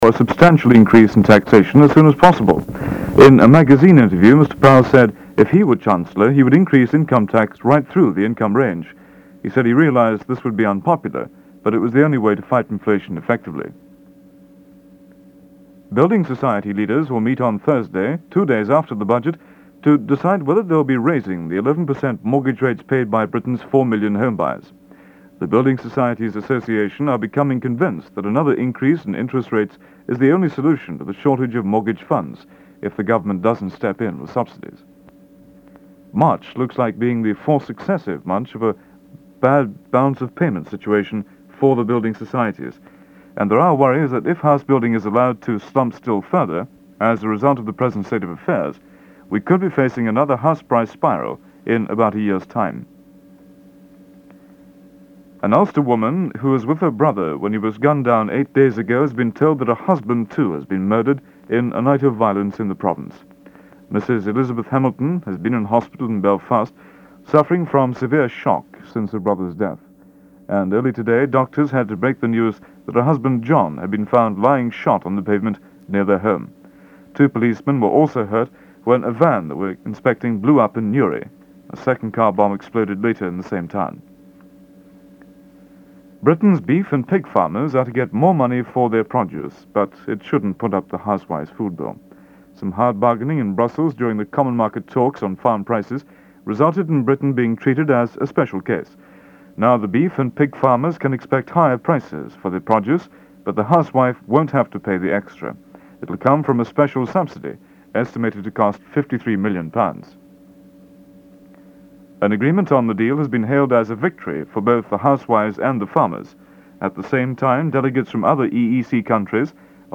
1974ThisisLondon-Wembley.MP3